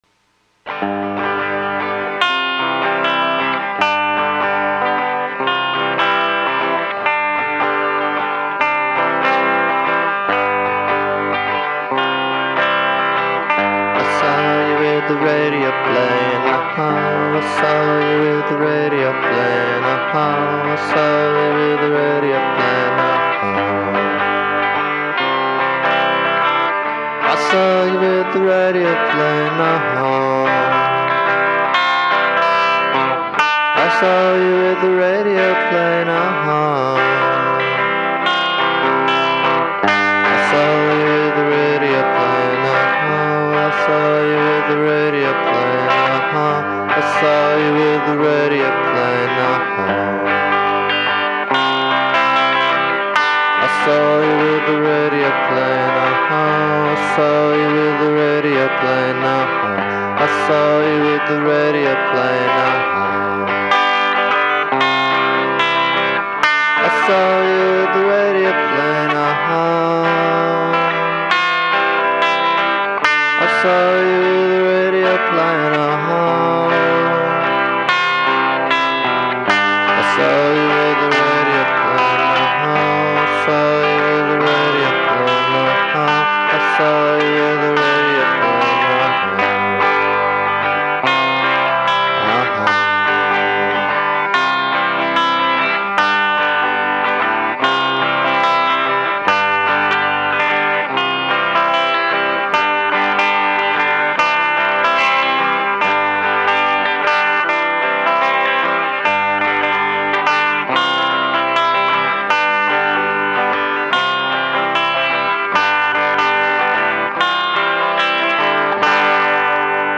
Tags: music folk pop original songs